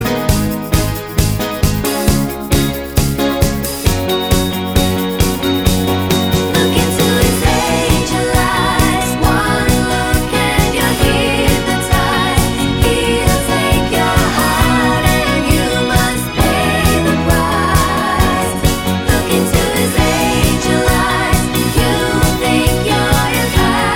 No Verse Harmonies Pop (1970s) 4:07 Buy £1.50